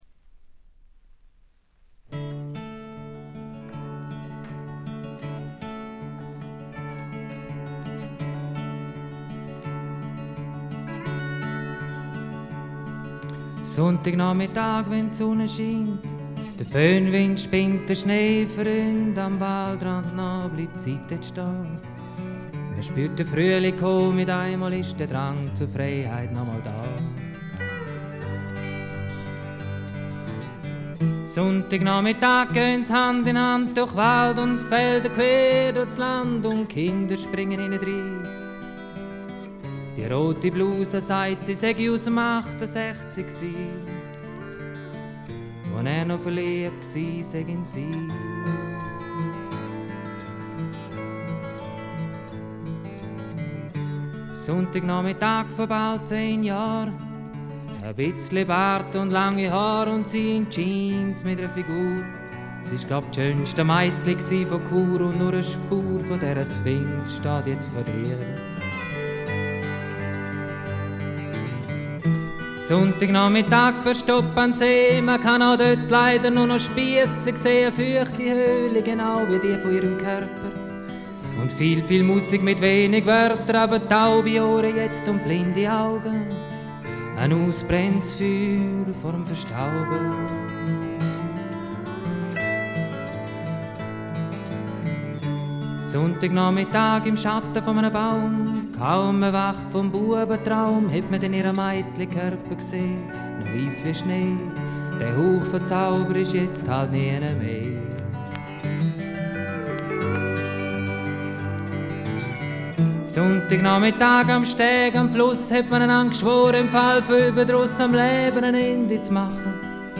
部分試聴ですが、軽微なチリプチが少し出る程度。
スイスのフォーク・フェスティヴァルの2枚組ライヴ盤。
試聴曲は現品からの取り込み音源です。